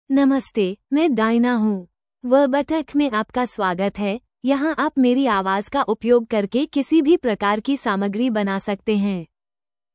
Diana — Female Hindi (India) AI Voice | TTS, Voice Cloning & Video | Verbatik AI
DianaFemale Hindi AI voice
Diana is a female AI voice for Hindi (India).
Voice sample
Listen to Diana's female Hindi voice.
Diana delivers clear pronunciation with authentic India Hindi intonation, making your content sound professionally produced.